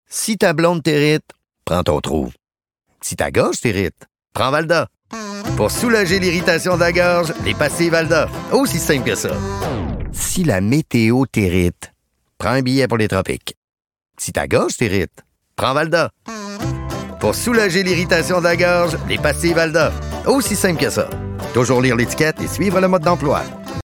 SPOT RADIO VALDA – 2
Copie-de-VALDA-SPOT3.mp3